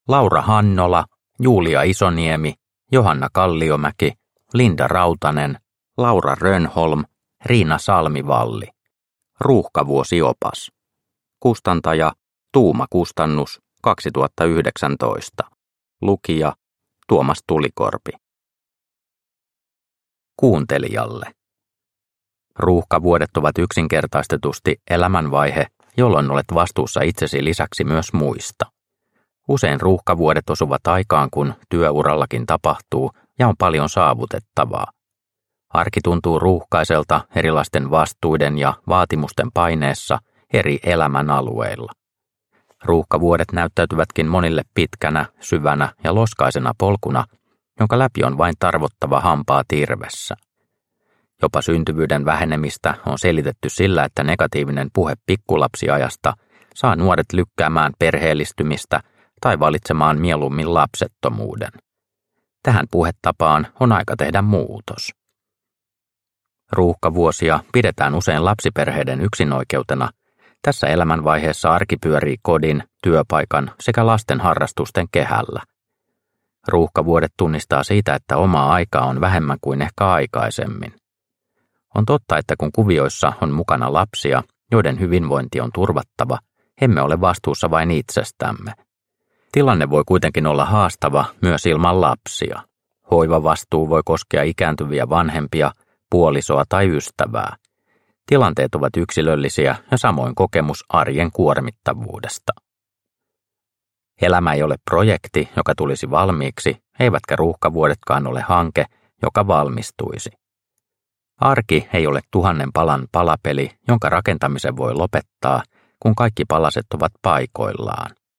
Ruuhkavuosiopas – Ljudbok – Laddas ner